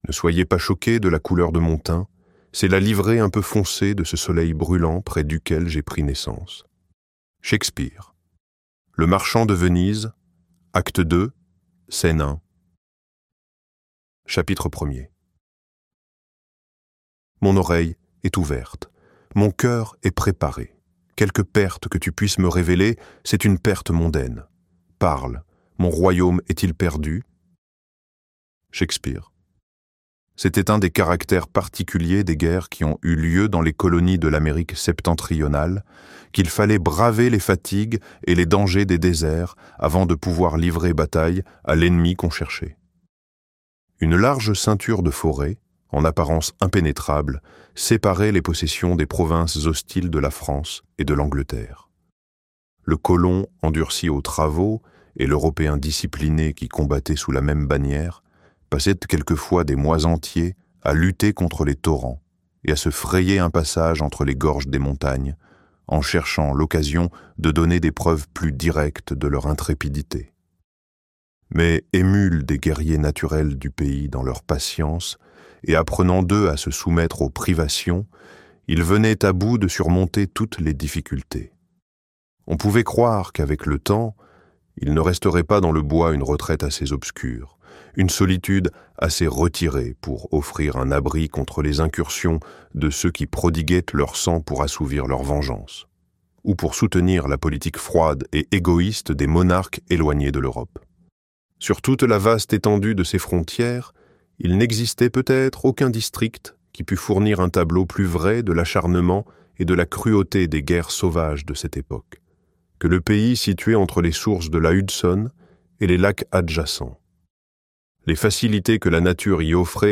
Le Dernier des Mohicans - Livre Audio